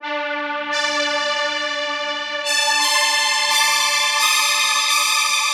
Vibes Strings 04.wav